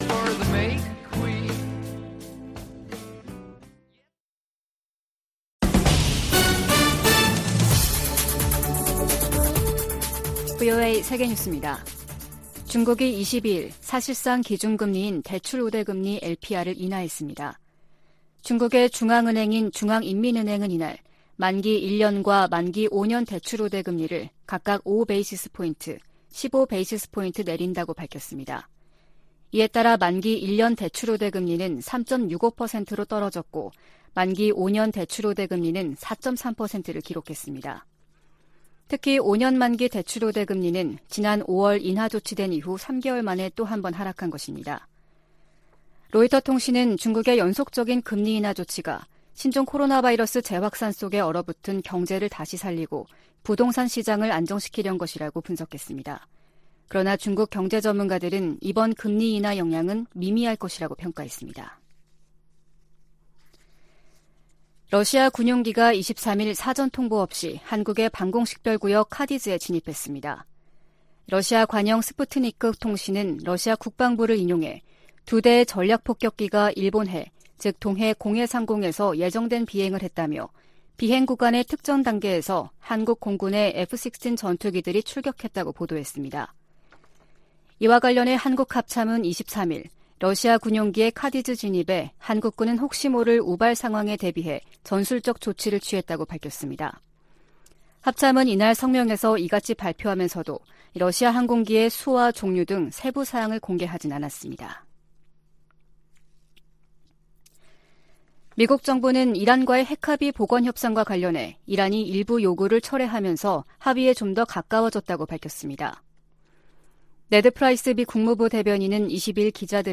VOA 한국어 아침 뉴스 프로그램 '워싱턴 뉴스 광장' 2022년 8월 24일 방송입니다. 미 국무부는 한국 윤석열 정부의 ‘담대한 구상’이 미국 정부의 접근법과 일치한다며, 북한의 긍정적 반응을 촉구했습니다. 한국이 사상 처음으로 호주의 대규모 다국적 연합훈련인 피치블랙 훈련에 참가한다고 호주 국방부가 확인했습니다. 중국이 주한미군의 고고도 미사일 방어체계 즉 사드(THAAD)에 관해 한국에 압박을 이어가고 있습니다.